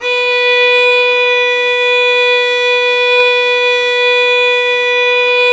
Index of /90_sSampleCDs/Roland - String Master Series/STR_Violin 4 nv/STR_Vln4 % marc